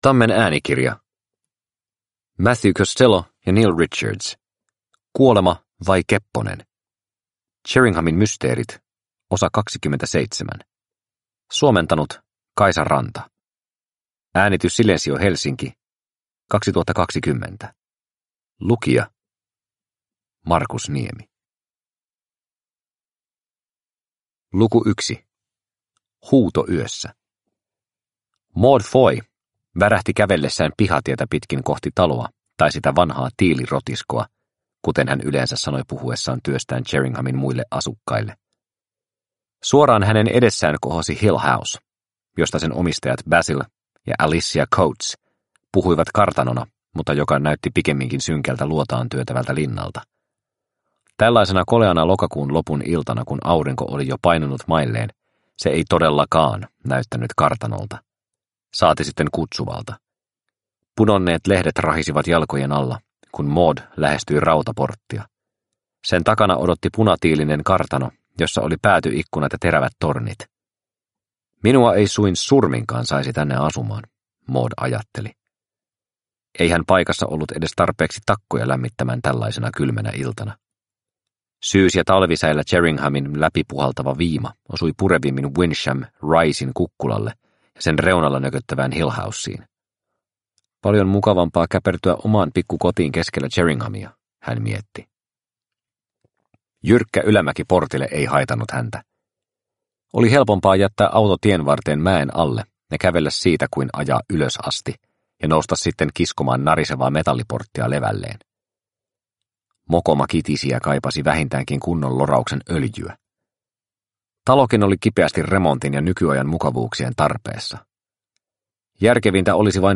Kuolema vai kepponen – Ljudbok – Laddas ner